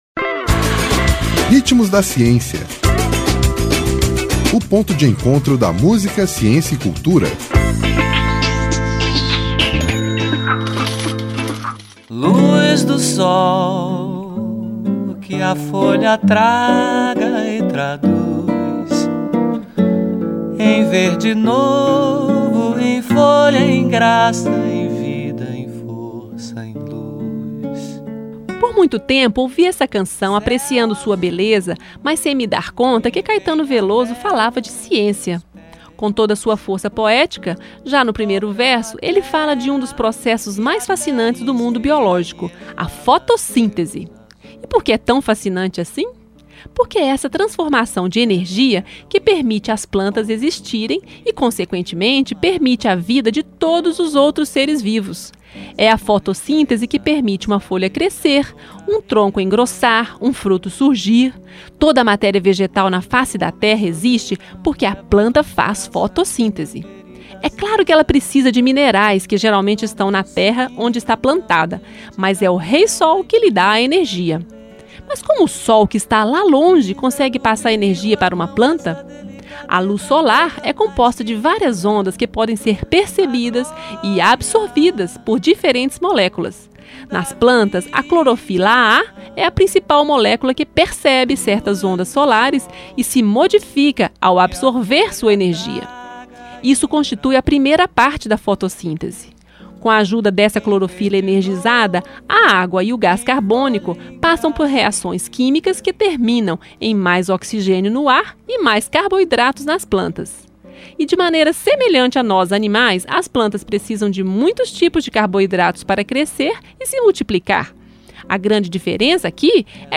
Texto e voz